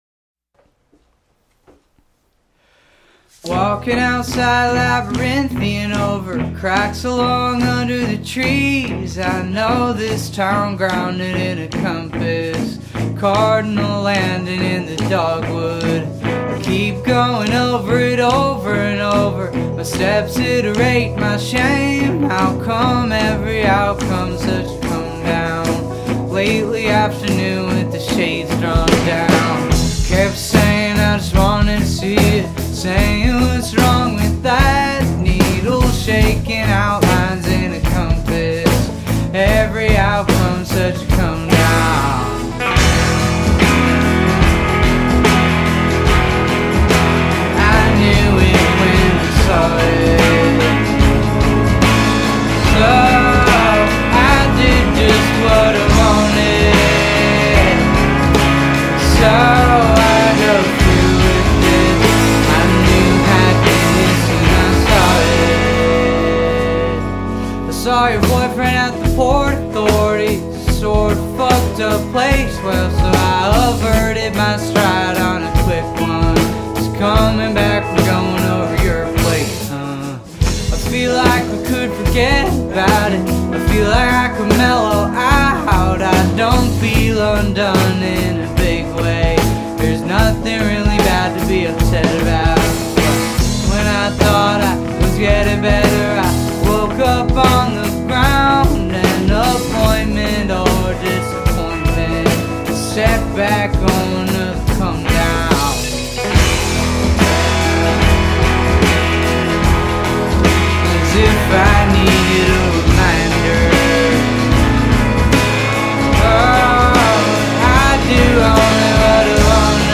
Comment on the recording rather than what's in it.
lead off studio track